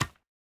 resin_brick_step3.ogg